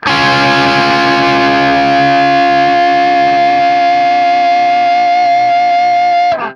TRIAD C#  -R.wav